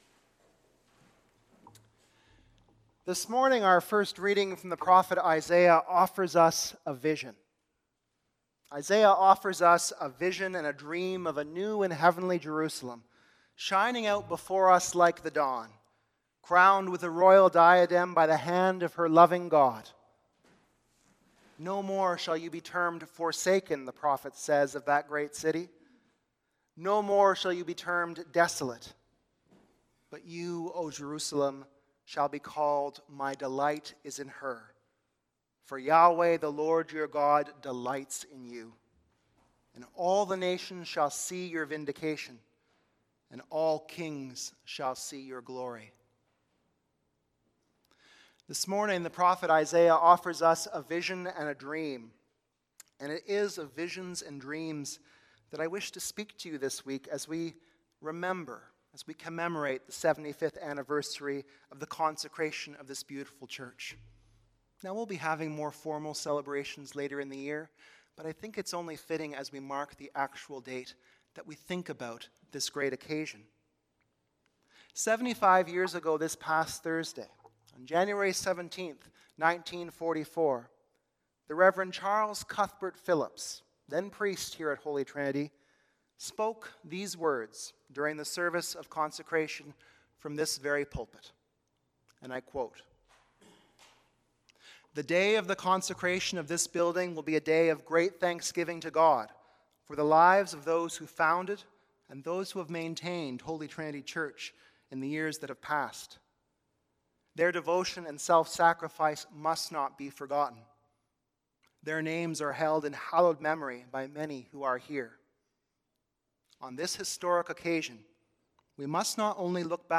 Sermons | Parish of the Valley